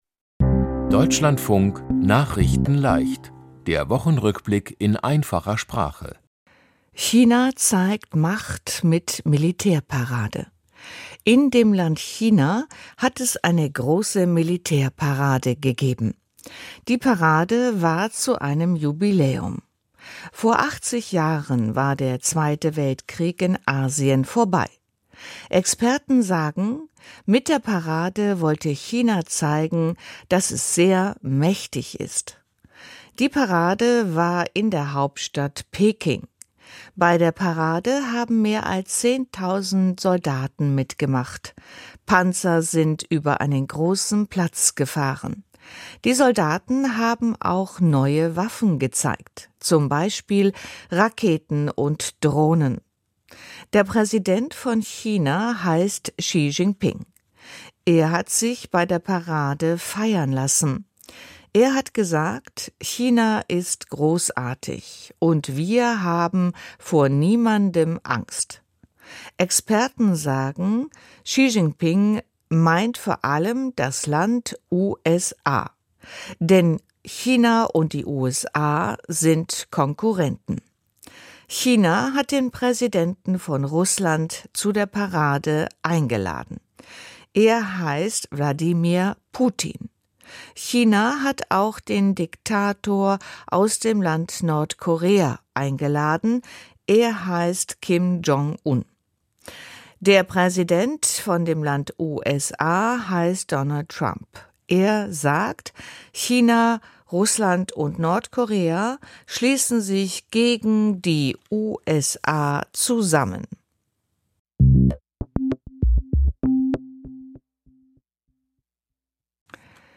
nachrichtenleicht - der Wochenrückblick in einfacher Sprache.